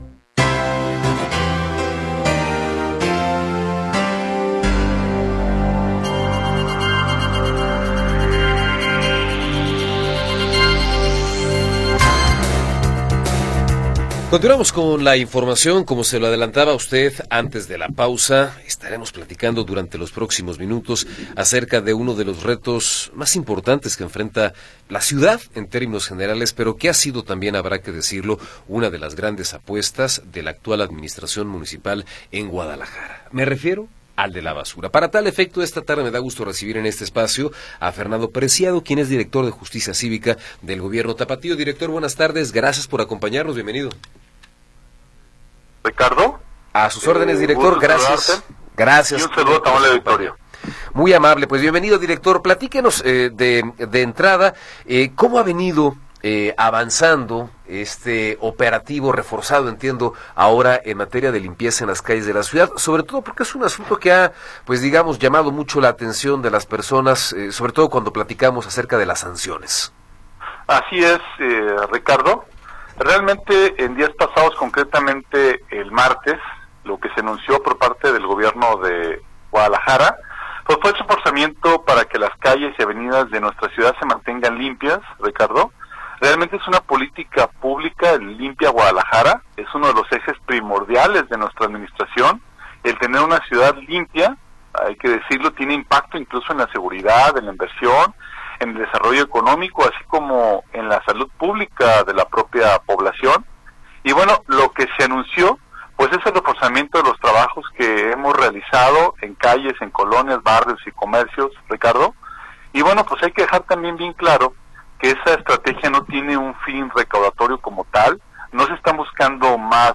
Entrevista con Fernando Preciado
Fernando Preciado, director de Justicia Cívica del Gobierno de Guadalajara, nos habla sobre las sanciones a quienes tiran basura en las calles de la ciudad.